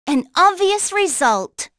Hanus-Vox_Victory_b.wav